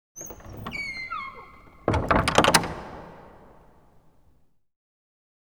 originally i wanted to use it for correcting wrong recorded sounds. church bells and especially doors are usually recorded very close, which results in a clear and loud attack phase. when wanting to use that sound for distant shots itīs necessessary to get rid of that detailed attack. just adding reflections and reverb doesnīt truely change perspective. first tries with doors are very promising, a church bell distorted to much.
try it with that door. it works brilliantly!!